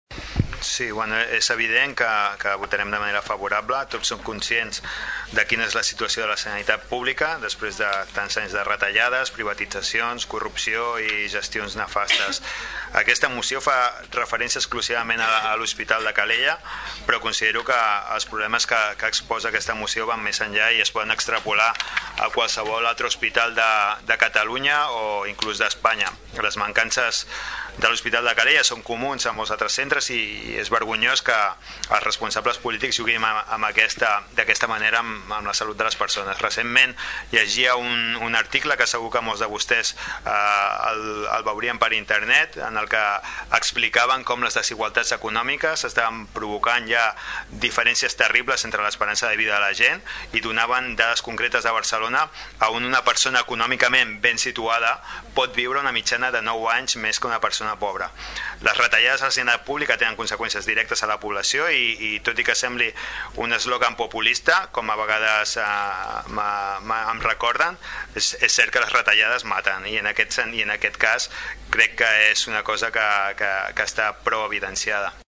Des del grup de SOM Tordera, Salvador Giralt criticava la situació actual i la manca de totalitat assistencial a l’Hospital de Calella degut a la falta d’inversió que s’ha fet fins ara.